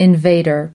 21 invader (n) /ɪnˈveɪdər/ Kẻ xâm lược